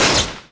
accretia_guardtower_critic.wav